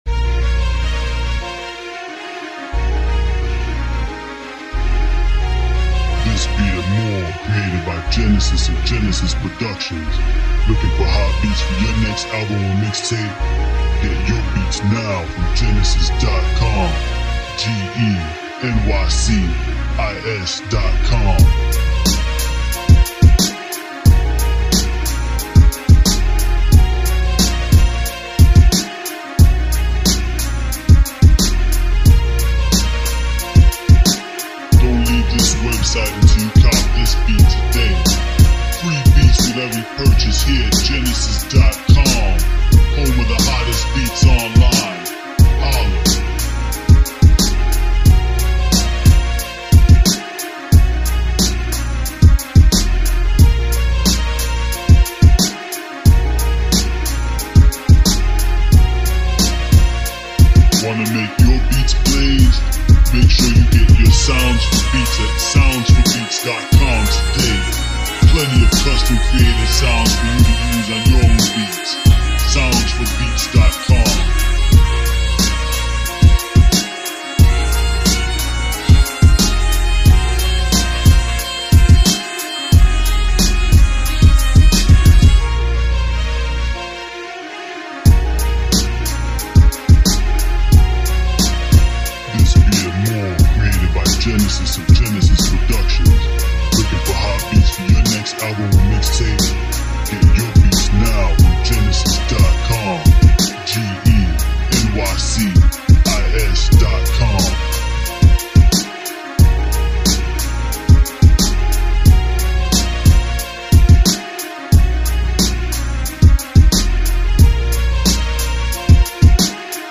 Instrumental Style Beat